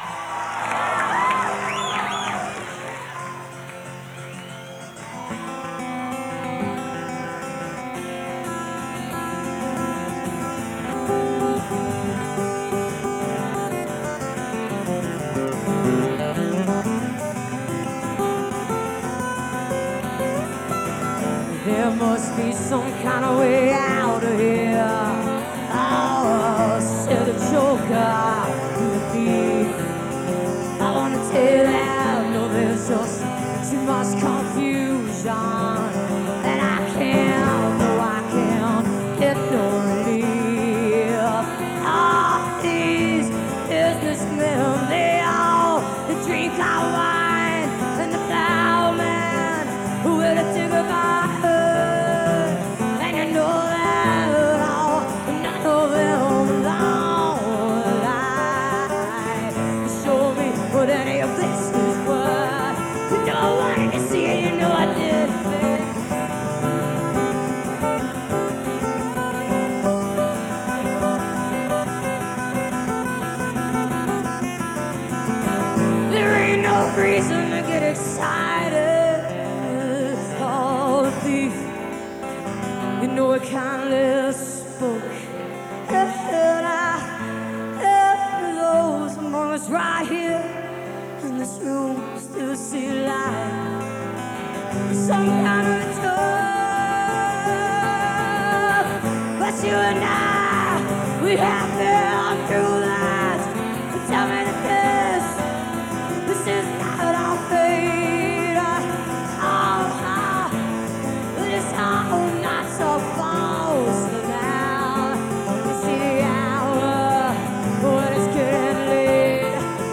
(radio broadcast source)